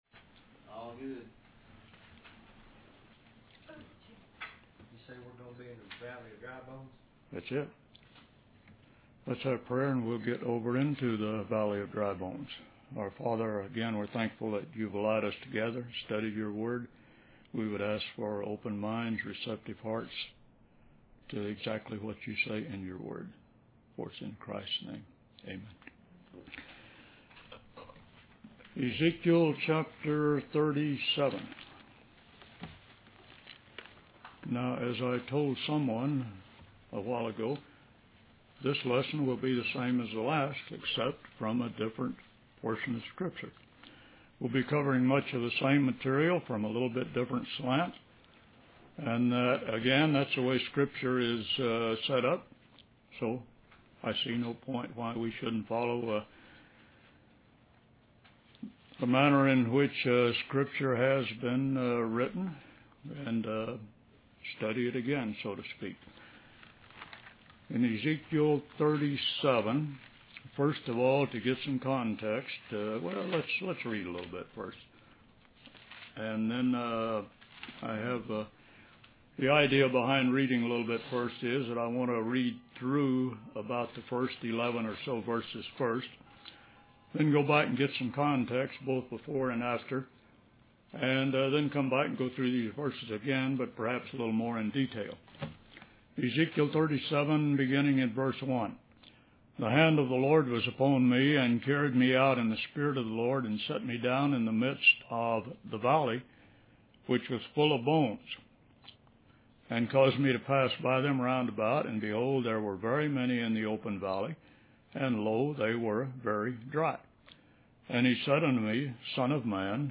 In this sermon, the preacher discusses the story of Lot and the destruction of Sodom and Gomorrah. He emphasizes the importance of following God's instructions and not looking back or staying in places of danger. The preacher then transitions to the book of Ezekiel, specifically chapter 37, where he talks about the vision of the Valley of Dry Bones.